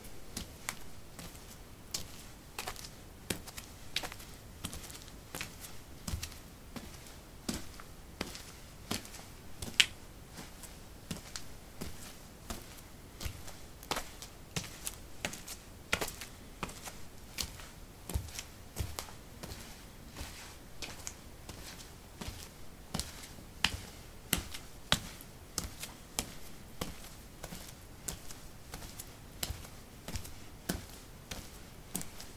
脚步声 混凝土 " 混凝土 02A 袜子的行走
描述：走在混凝土上：袜子。在房子的地下室用ZOOM H2记录，用Audacity标准化。
Tag: 脚步 步骤 混凝土 袜子 脚步